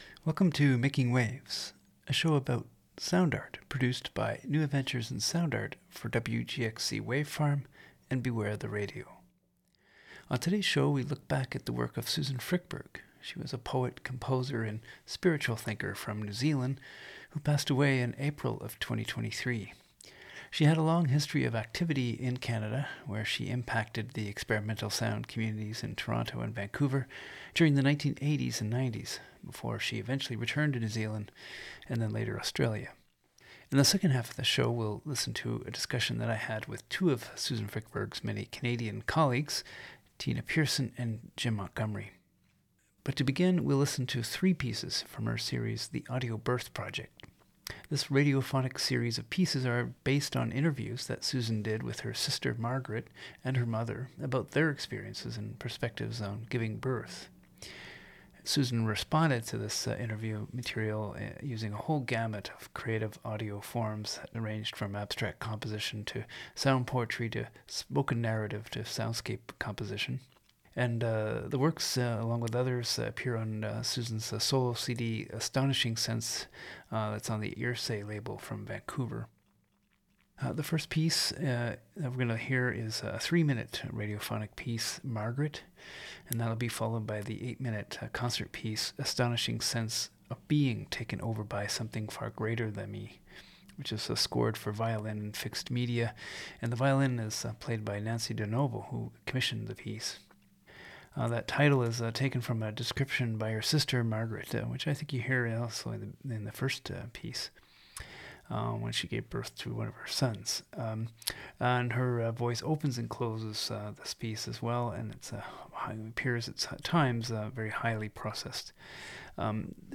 It begins with a radiophonic series of pieces she made
interview